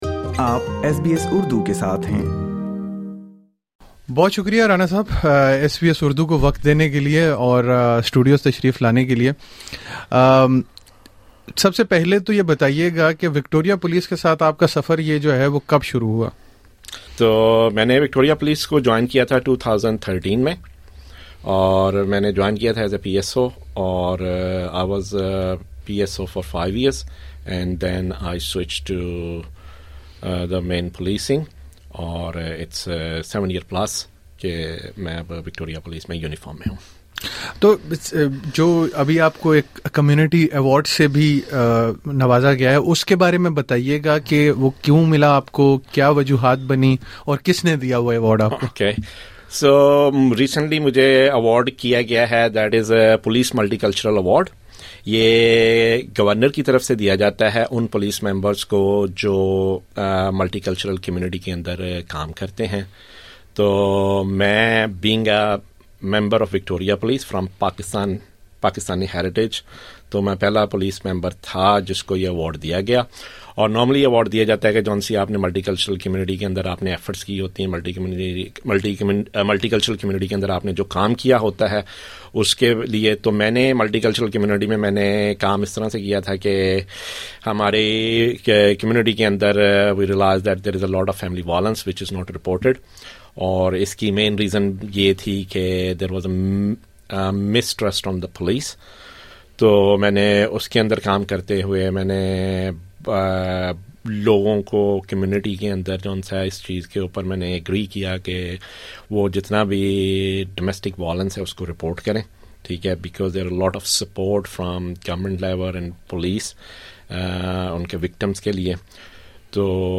ایس بی ایس اردو سے خصوصی گفتگو میں انہوں نے پولیس کے بارے میں مختلف سوالات کا جواب دیا ہے۔